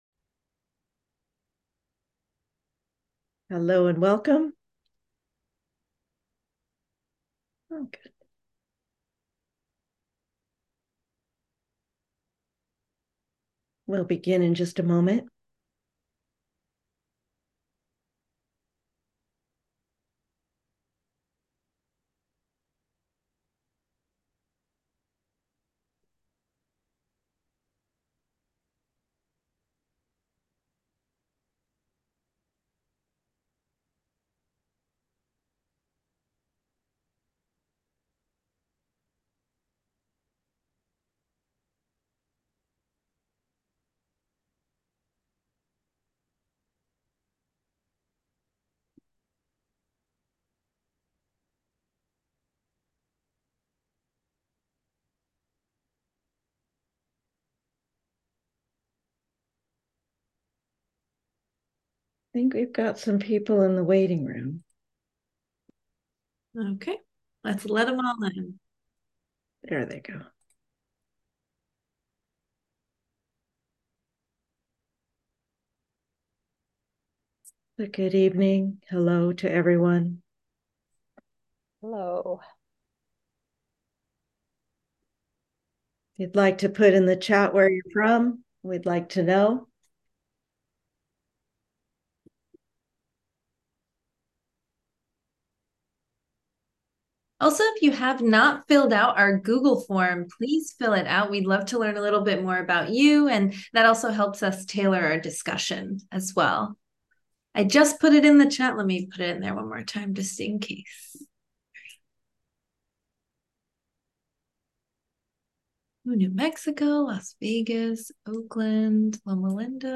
Intro to Integrative Health 101 Live Webinar 11.14.23